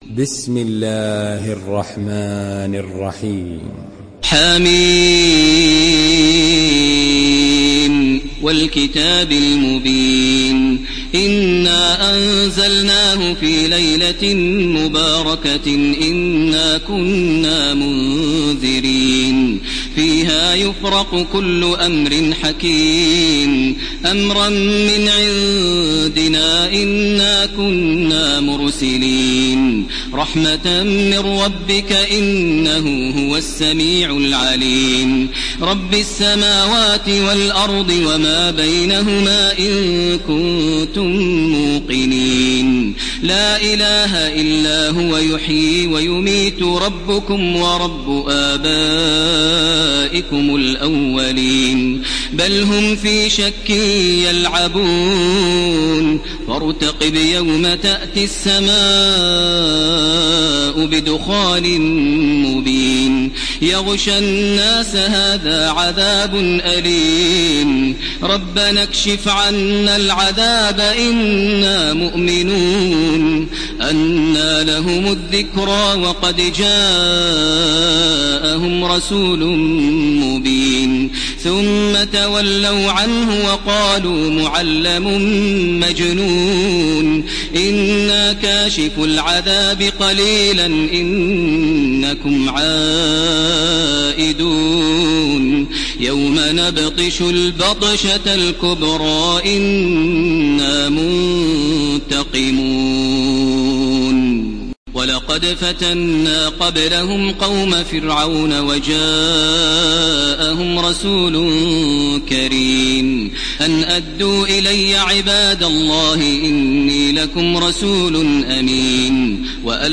Surah الدخان MP3 by تراويح الحرم المكي 1431 in حفص عن عاصم narration.
مرتل